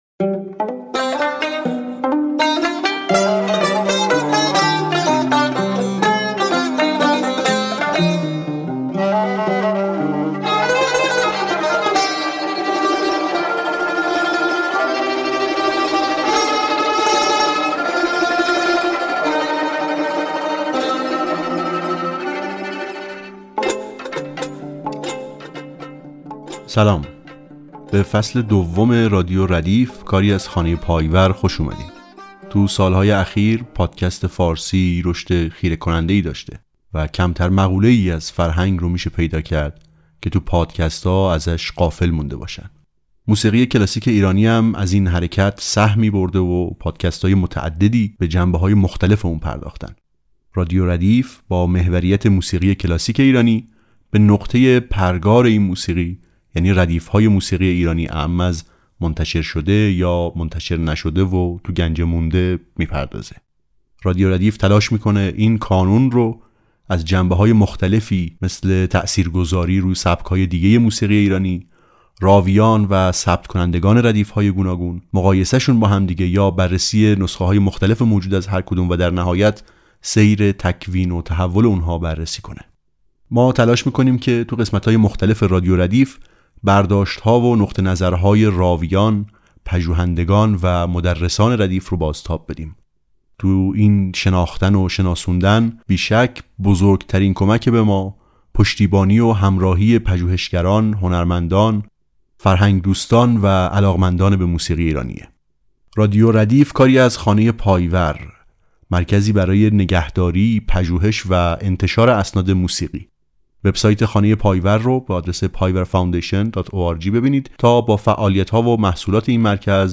دبیر اجرا و گوینده
نویسنده و گوینده